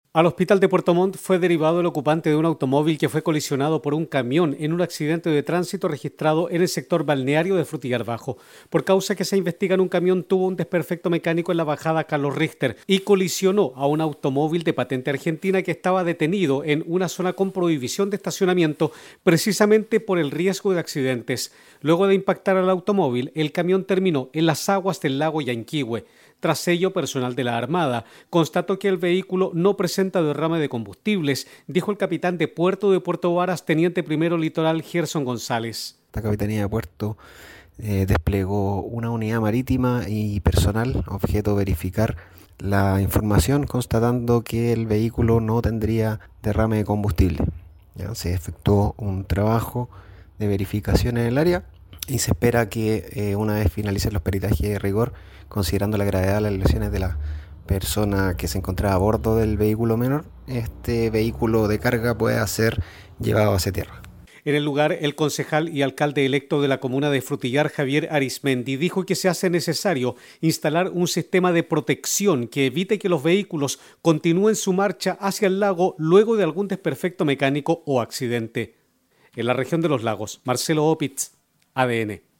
Un camión de carga terminó en el interior del Lago Llanquihue tras sufrir una falla mecánica que afectó su sistema de frenos en la comuna de Frutillar. El accidente quedó captado por la cámara de un transeúnte.